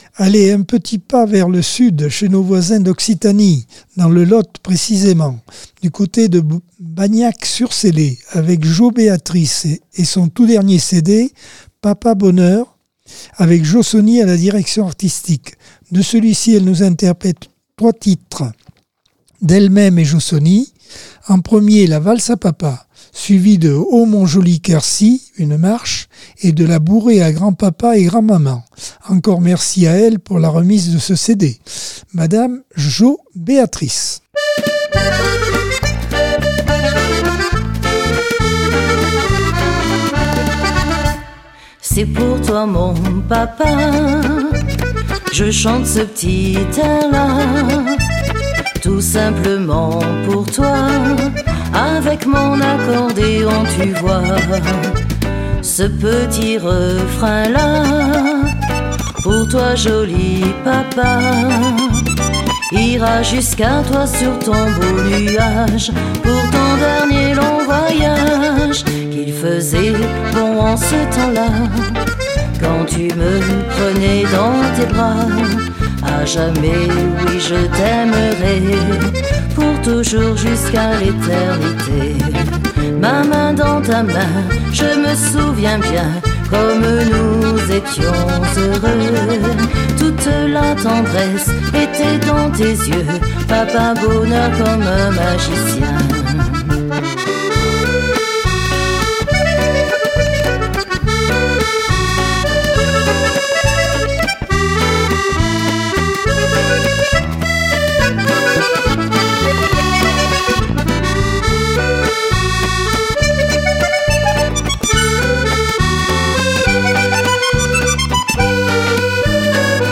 Accordeon 2024 sem 19 bloc 2 - Radio ACX